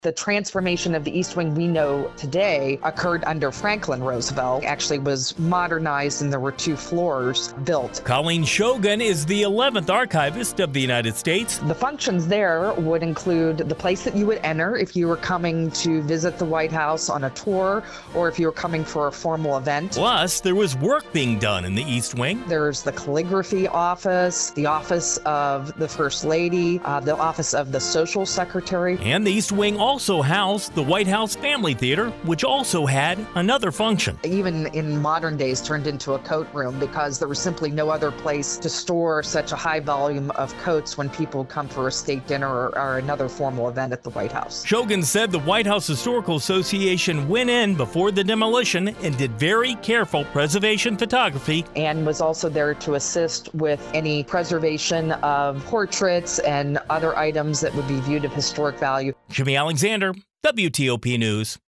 2-east-wing-archivist.wav